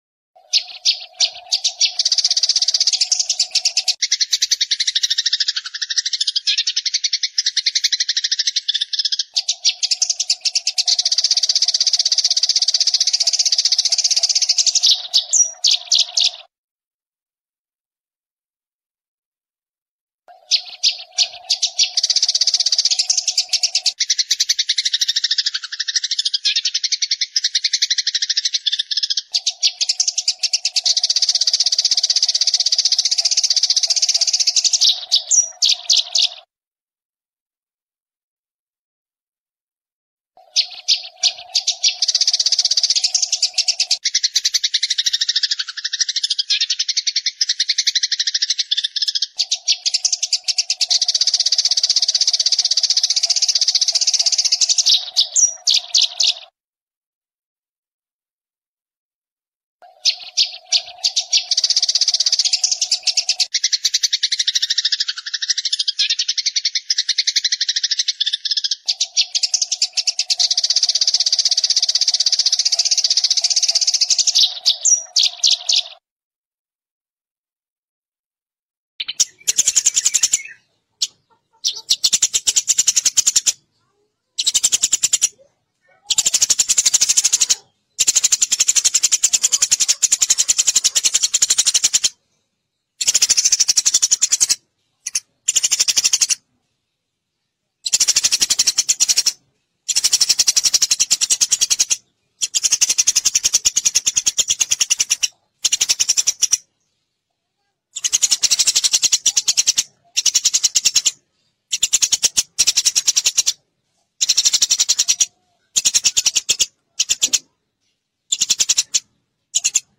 Suara Burung Gereja Betina Jernih
Kategori: Suara burung
Keterangan: Download Suara Burung Gereja Betina Memanggil Jantan Gacor MP3 Jernih – Nikmati keindahan suara burung gereja betina yang memanggil jantan dengan kualitas audio jernih.
suara-burung-gereja-betina-jernih-id-www_tiengdong_com.mp3